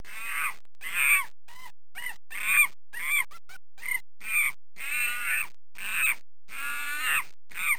Monkey